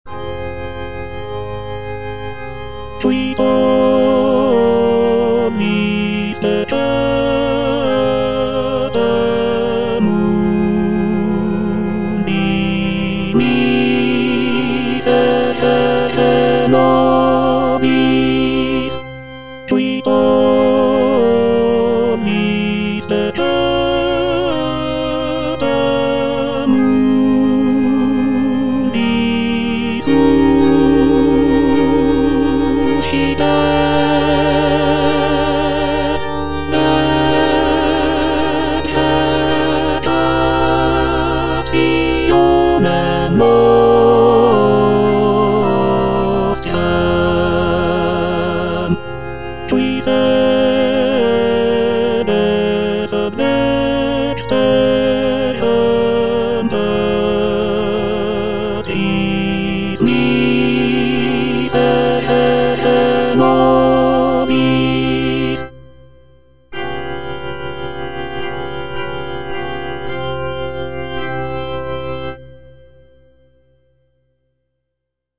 Tenor (Emphasised voice and other voices) Ads stop
choral work